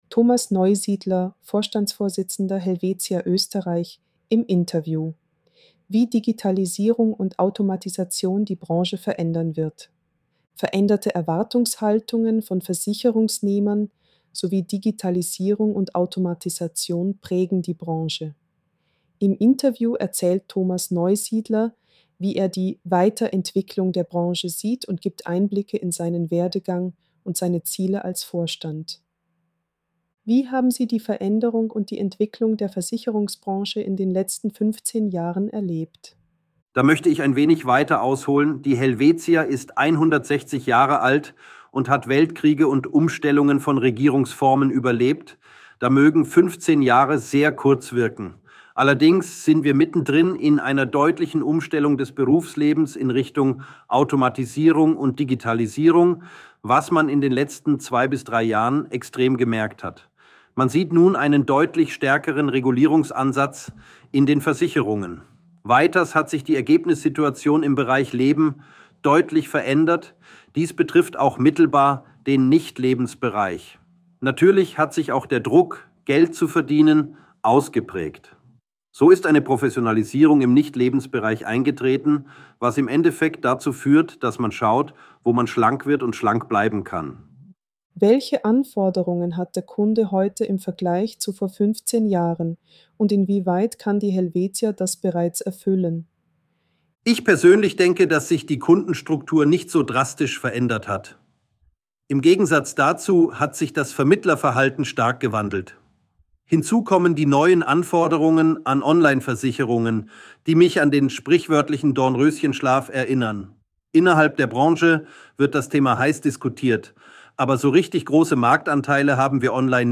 Veränderte Erwartungshaltungen von Versicherungsnehmern sowie Digitalisierung und Automatisation prägen die Branche. Im Interview